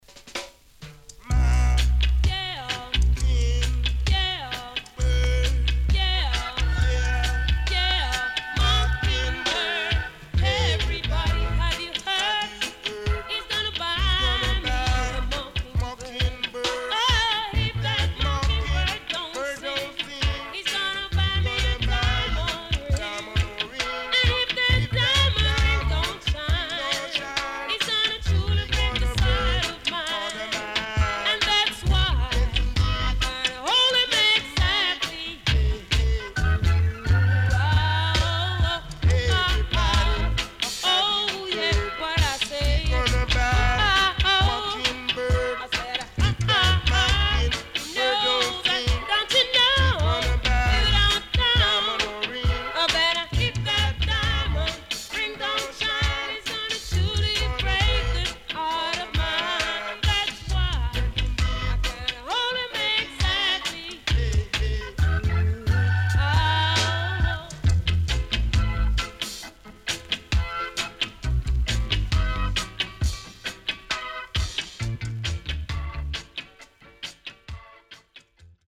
HOME > REGGAE / ROOTS
CONDITION SIDE A:VG(OK)〜VG+
SIDE A:かるいヒスノイズ入ります。